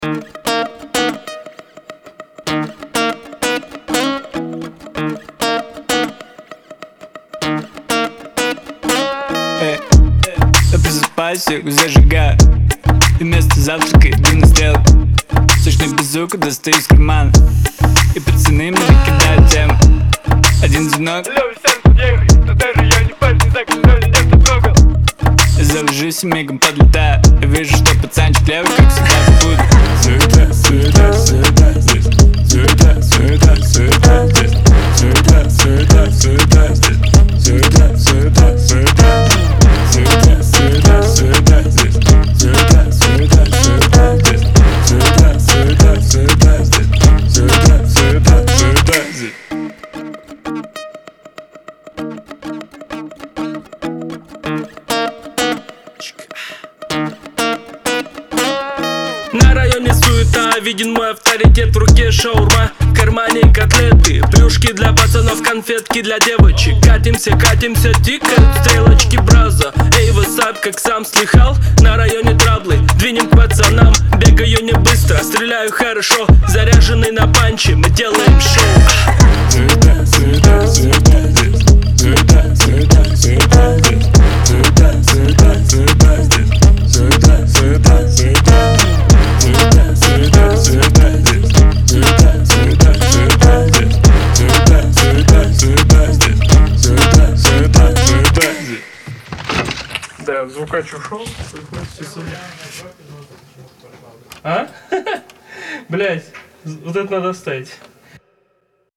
это эмоциональный трек в жанре альтернативного рокa